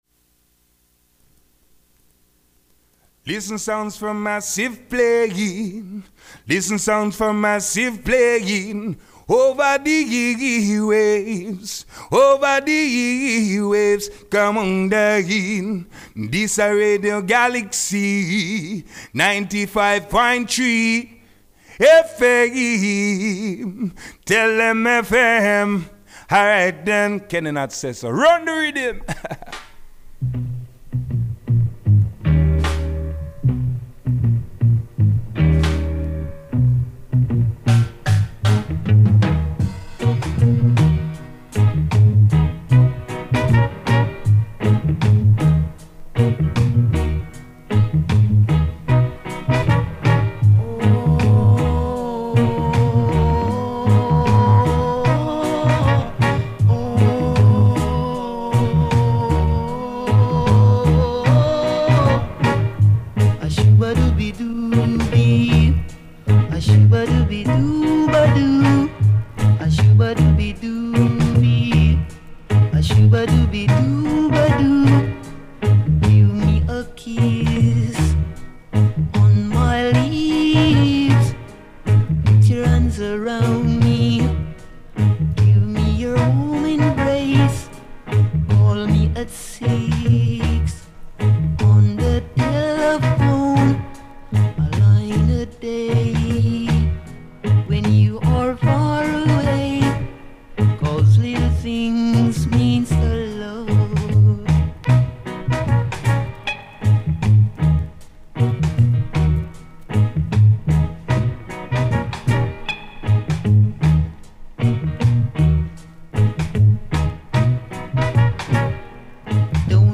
reggaephonique
enregistré lundi 8 mai dans les studios
Sélection roots 100% vinyles Ecoute, profite et partage